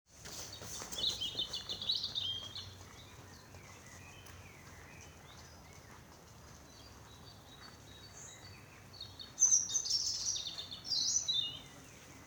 Im Volkspark Friedrichshain
Rotkehlchen in Berlin
Rotkehlchen.mp3